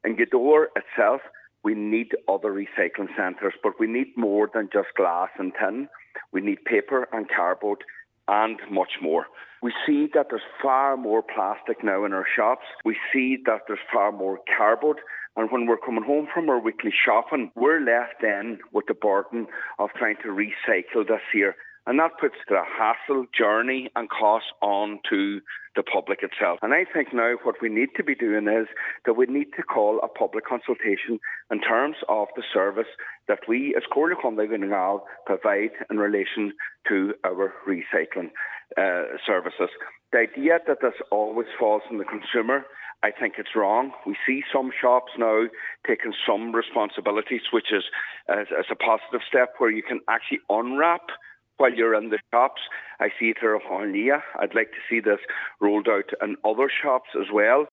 Speaking to Highland Radio, Cllr. Micheal Choilm Mac Giolla Easbuig appealed to the public to take their rubbish home if they can not properly dispose of it.